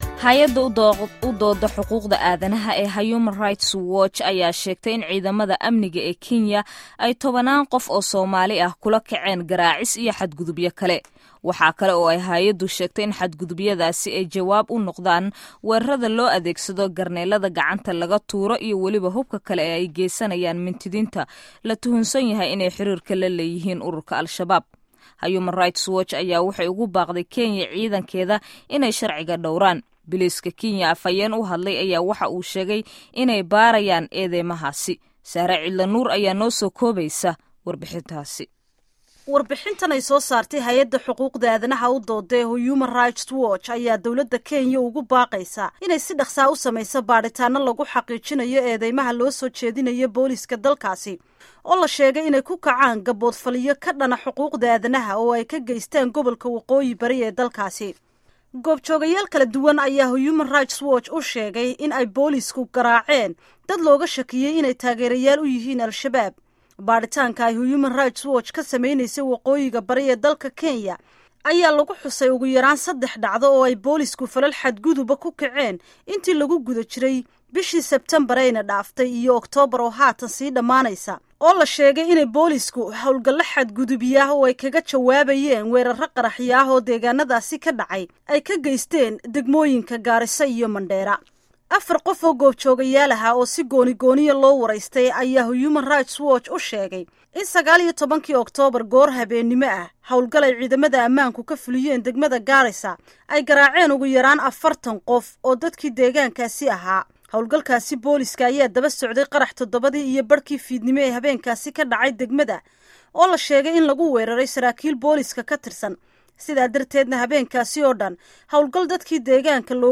Warbixinta Hay'adda HRW